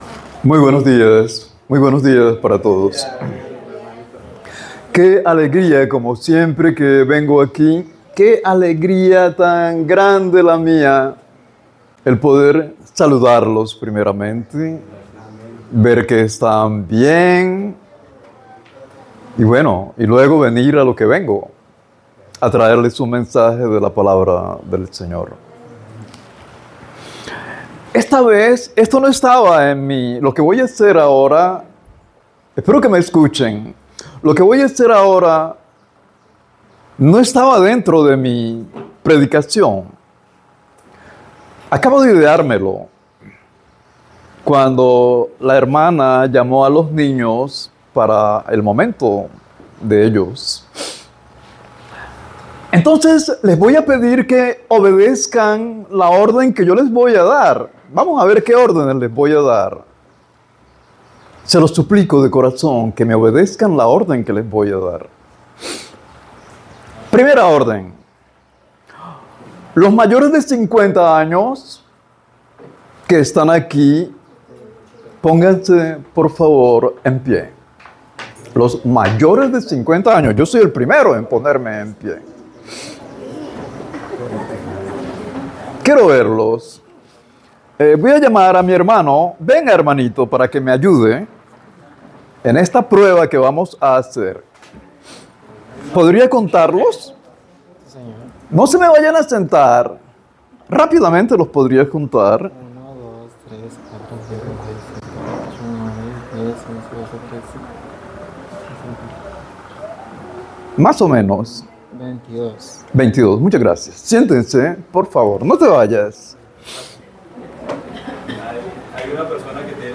Predicaciones Primer Semestre 2025